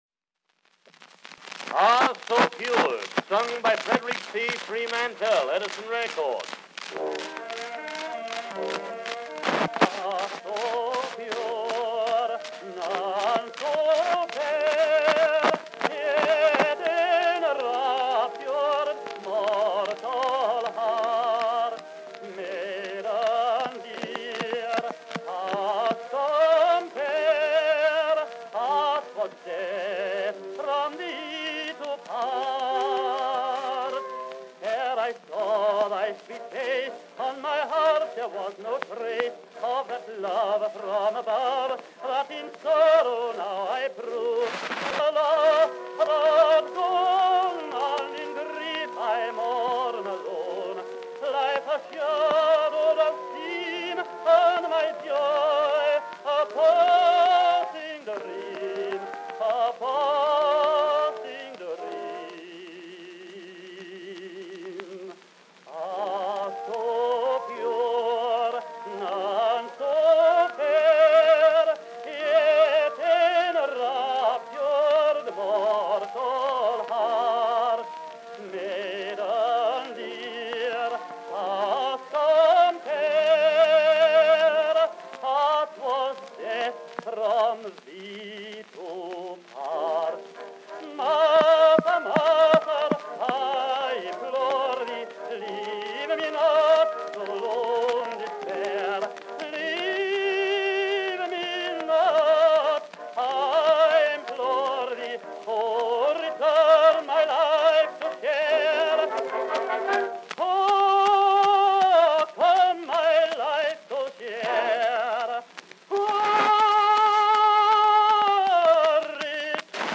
Tenor solo with orchestra accompaniment.
Operas—Excerpts.